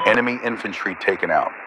Added "infantry killed" radio messages
pilotKillInfantry2.ogg